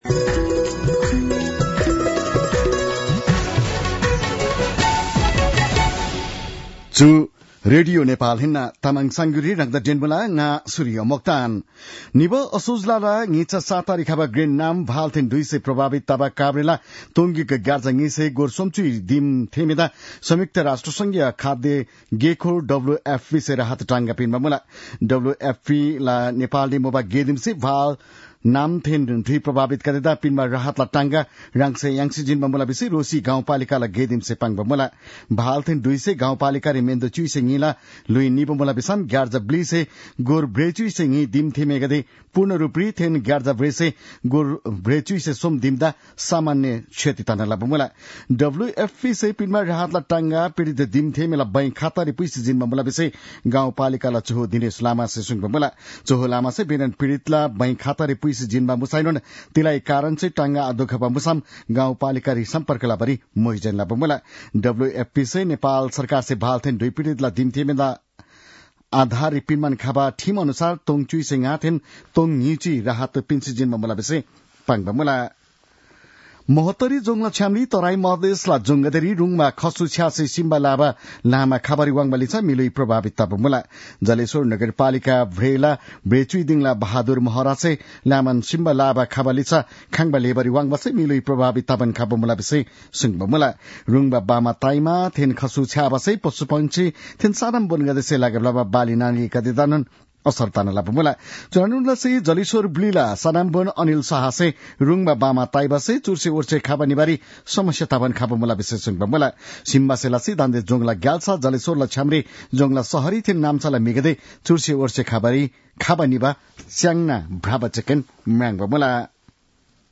तामाङ भाषाको समाचार : १७ पुष , २०८१